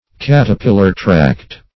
caterpillar-tracked - definition of caterpillar-tracked - synonyms, pronunciation, spelling from Free Dictionary